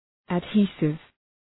Προφορά
{æd’hi:sıv}